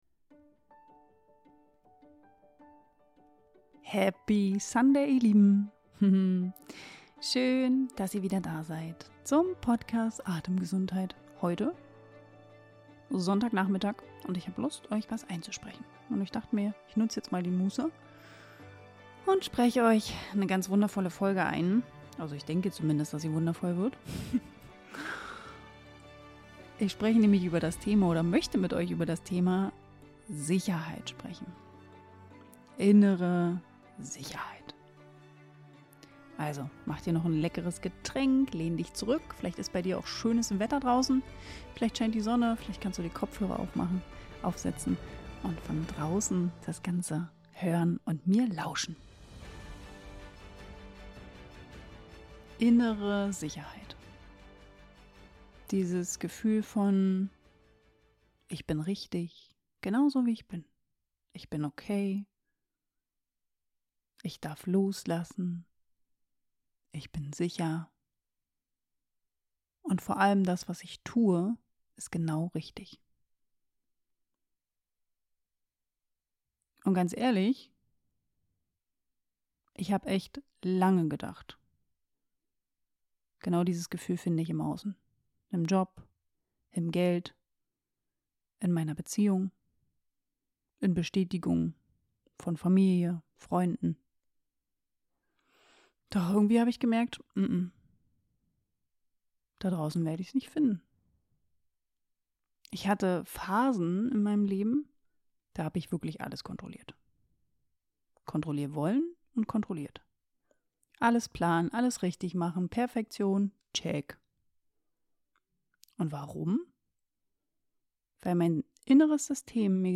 Sanft.